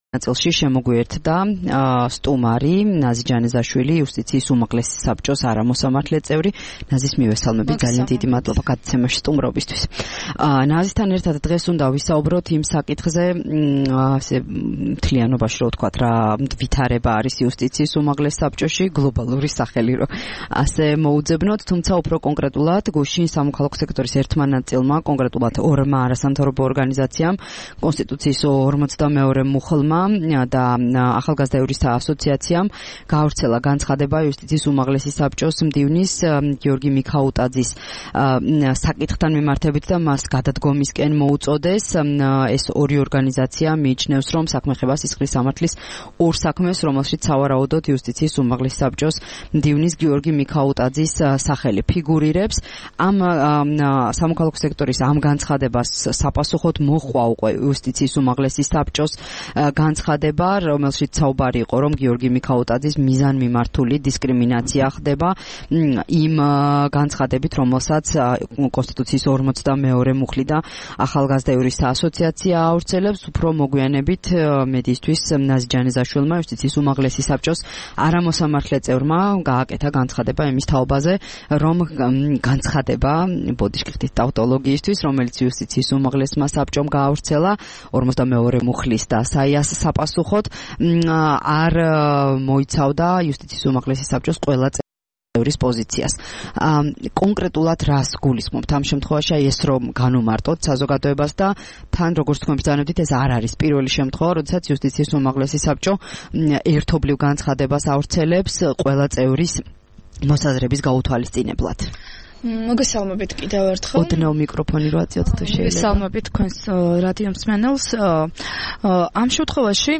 22 მარტს რადიო თავისუფლების „დილის საუბრების“ სტუმარი იყო ნაზი ჯანეზაშვილი, იუსტიციის უმაღლესი საბჭოს არამოსამართლე წევრი. ის გამოეხმაურა 21 მარტს იუსტიციის უმაღლეს საბჭოს სახელით გავრცელებულ განცხადებას.
საუბარი ნაზი ჯანეზაშვილთან